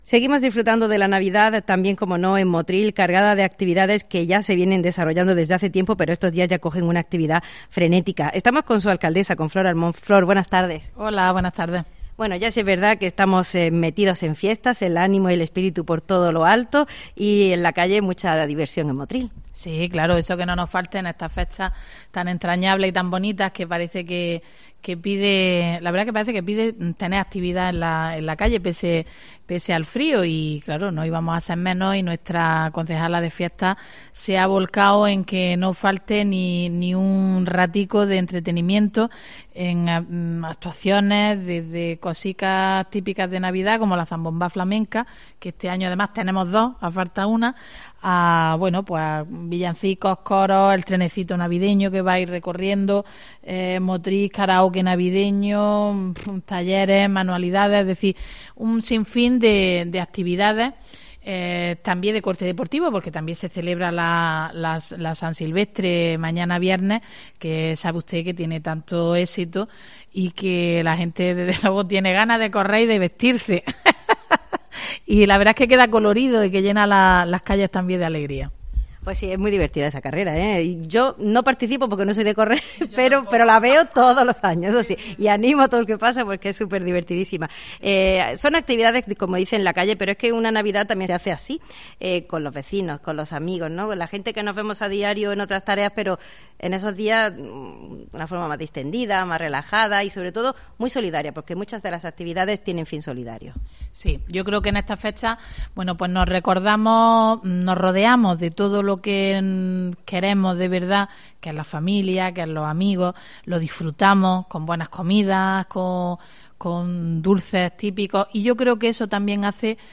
La alcaldesa Flor Almón, anima a los motrileños a salir a la calle estas Navidades y participar en las muchas actividades programadas.